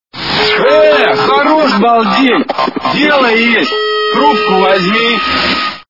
» Звуки » Смешные » Хорош балдеть - трубку врзьми!
При прослушивании Хорош балдеть - трубку врзьми! качество понижено и присутствуют гудки.